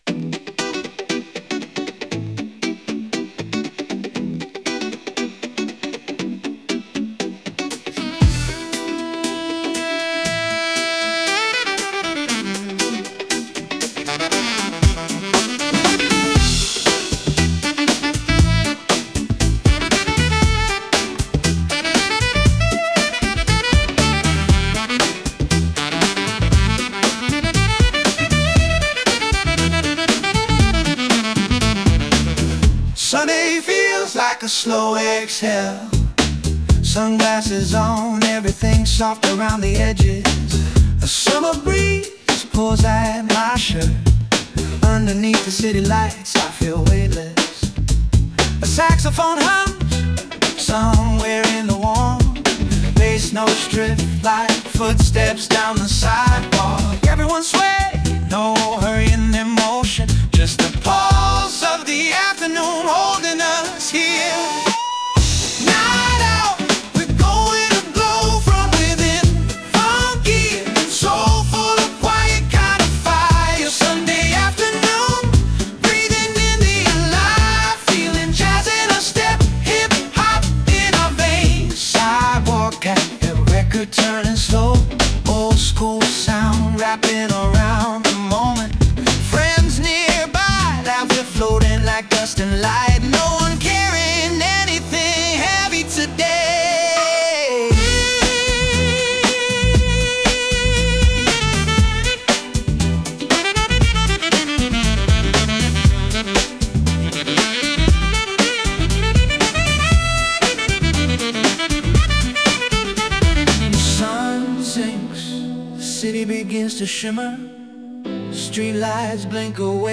The time has finally come: “Sunday” was produced exclusively with local and open source software (opens in a new tab) , no subscriptions or internet required anymore. The magic comes from the open source foundation model Ace-Step-1.5 (opens in a new tab) and for this song in particular I worked with ComfyUI (opens in a new tab) as the user interface.